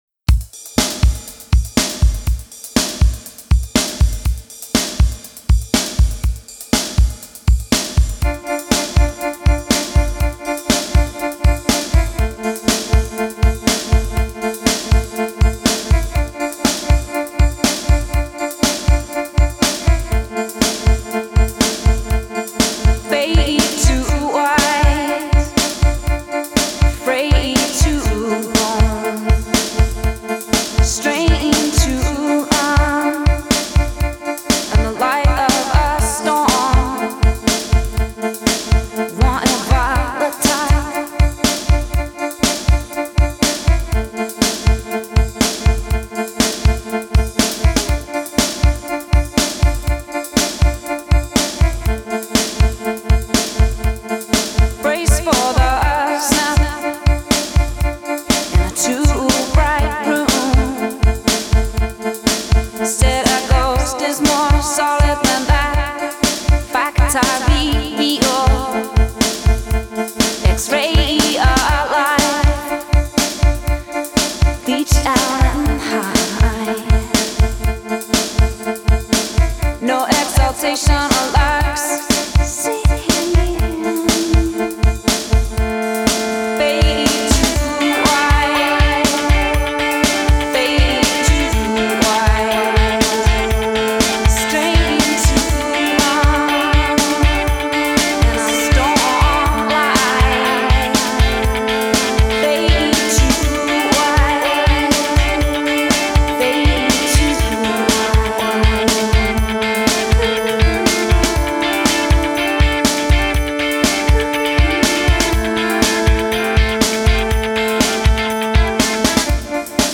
She’s got soul, she’s a folk singer and she’s classic.